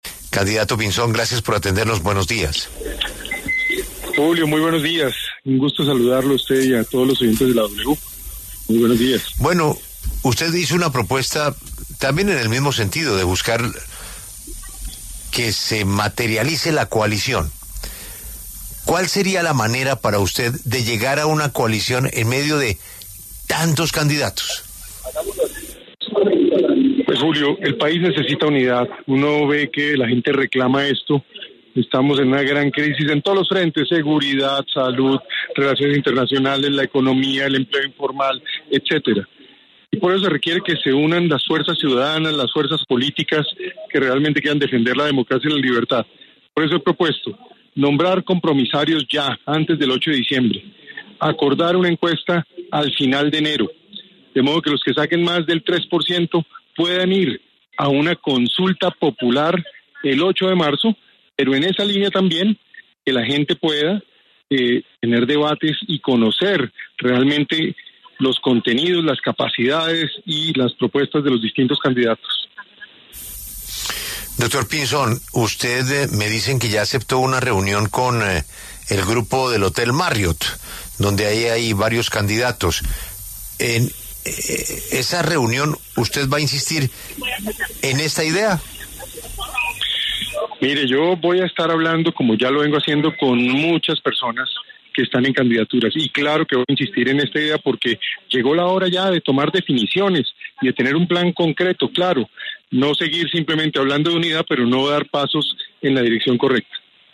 Juan Carlos Pinzón, precandidato presidencial, pasó por los micrófonos de La W.